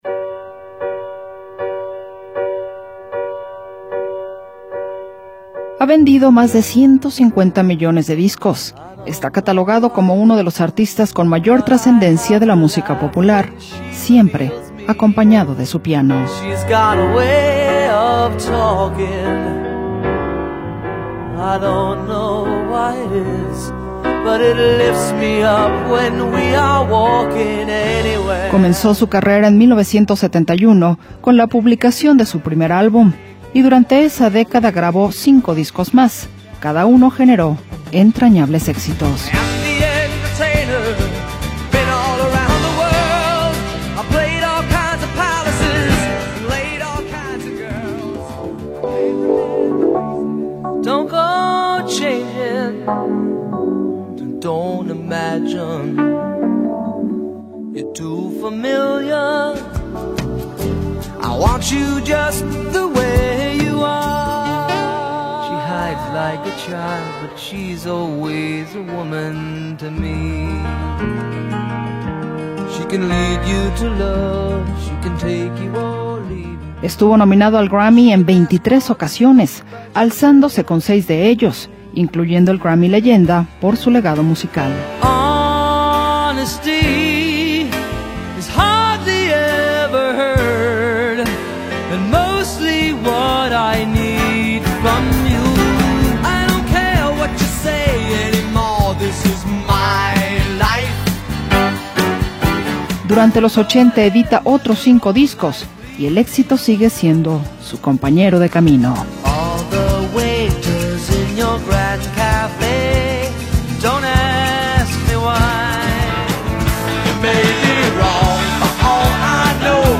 Su estilo combina rock, pop y balada con una narrativa lírica única.